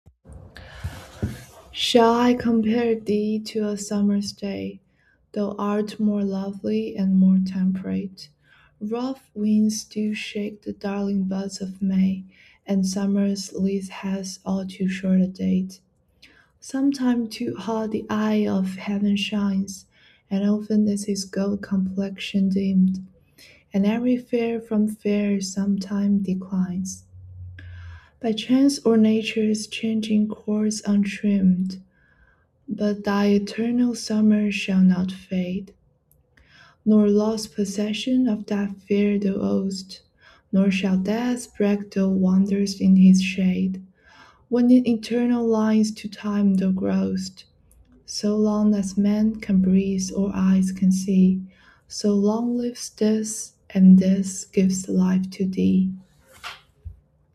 完全按照谷歌翻译的发音读的(ｰｰ;) 如果有下一个人回复的话，选一首舒婷的诗或者随便